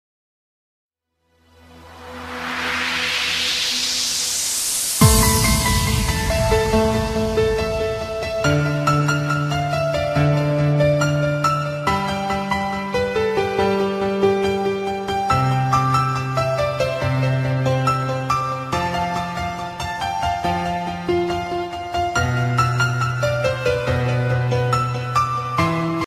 Скачано с discogs, как заставка к клипу.
Я так понимаю, что-то вроде отбивки.